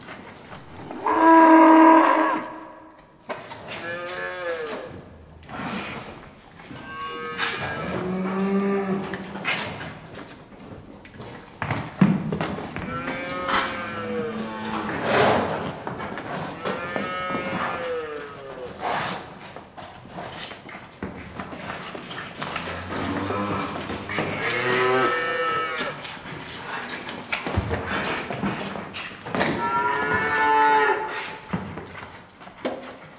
cowbarn.wav